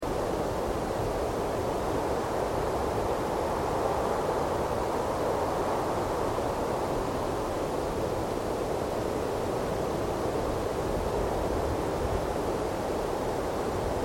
دانلود آهنگ باد 21 از افکت صوتی طبیعت و محیط
دانلود صدای باد 21 از ساعد نیوز با لینک مستقیم و کیفیت بالا
جلوه های صوتی